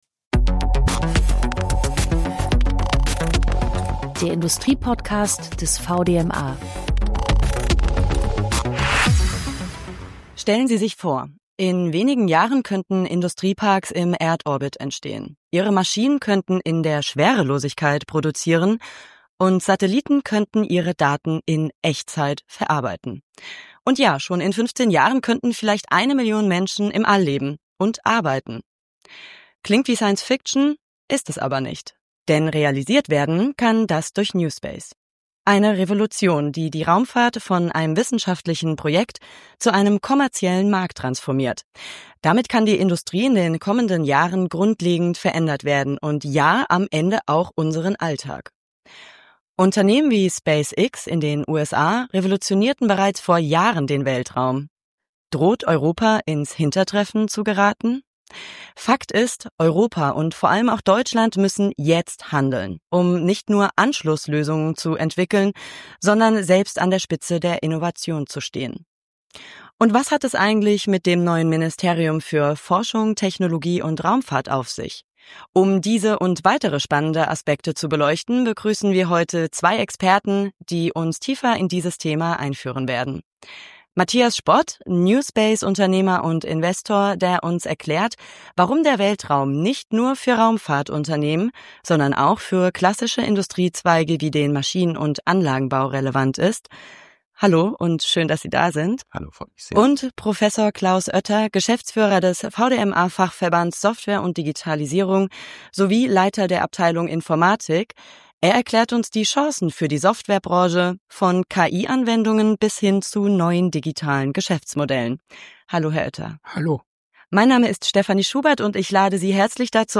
Dazu sprechen wir mit Expertinnen und Experten aus Forschung, Wissenschaft und Unternehmen.